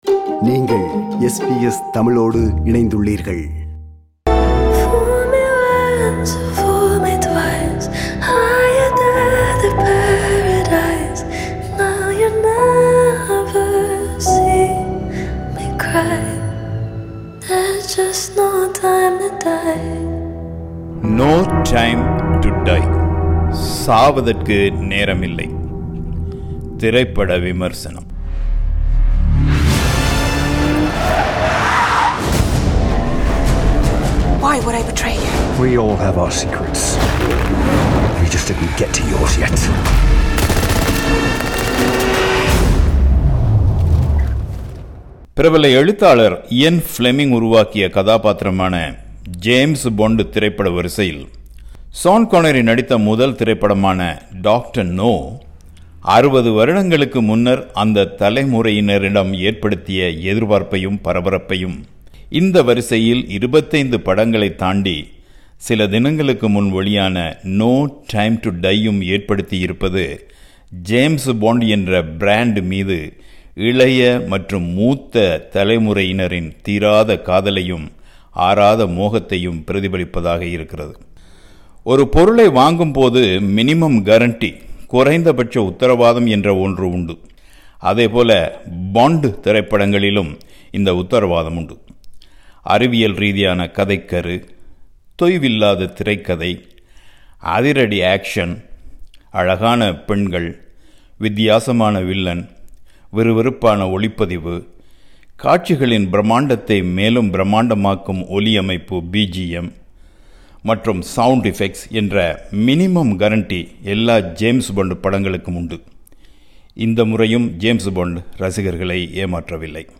No Time To Die: A review